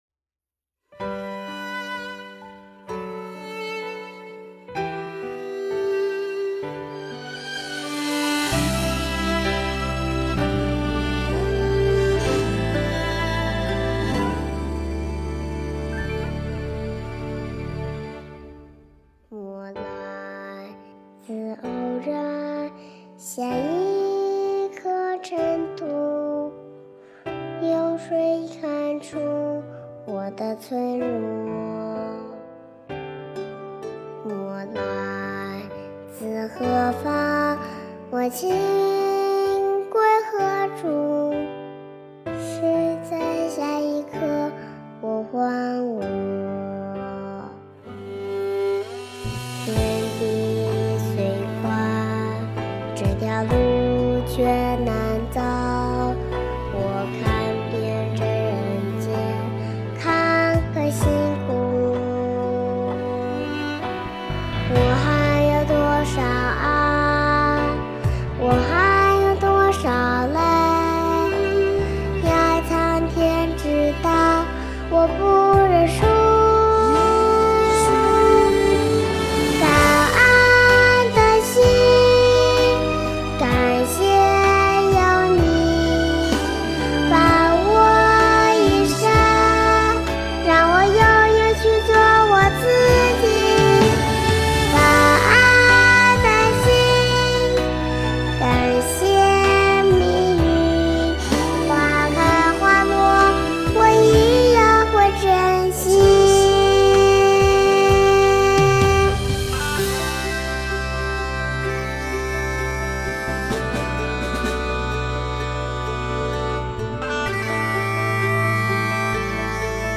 音频：比利時素食分享+瓷像開光2023年06月18日